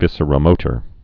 (vĭsər-ə-mōtər)